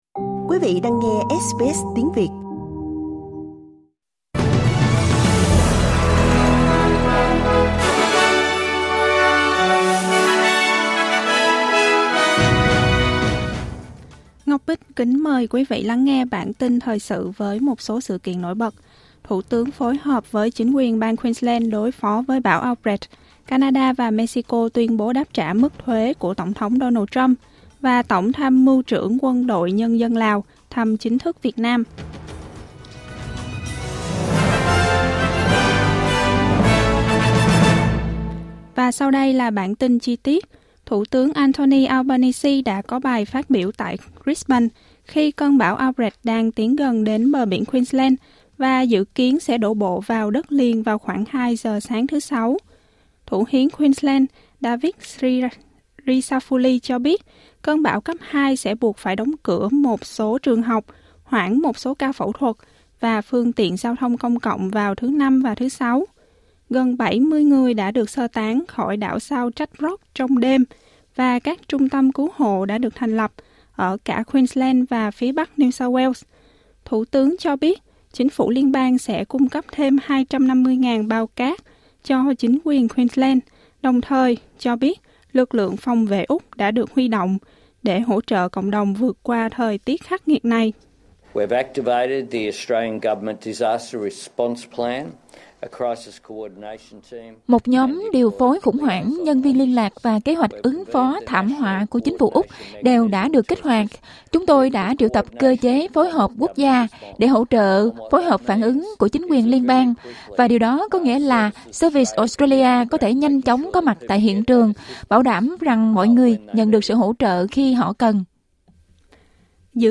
Một số tin tức trong bản tin thời sự của SBS Tiếng Việt.